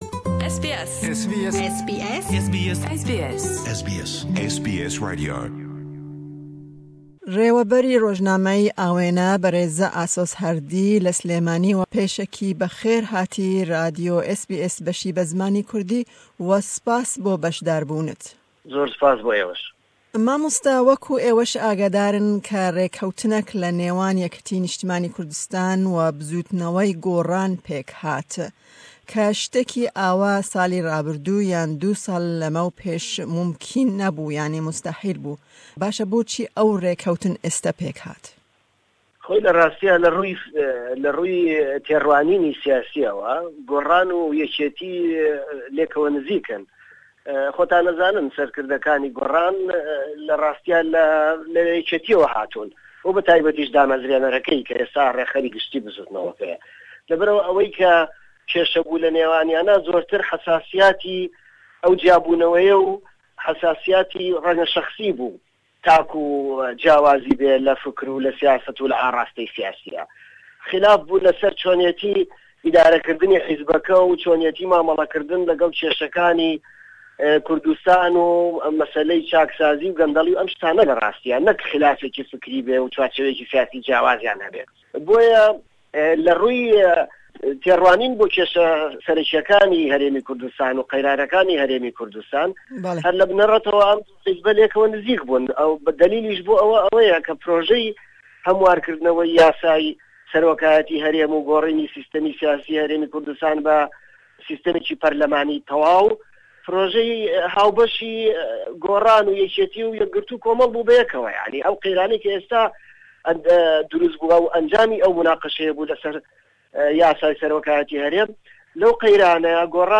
Hevpeyvîn sebaret bi rêkeftina di navberî YNK û Gorran de bû.